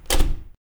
Lever1.ogg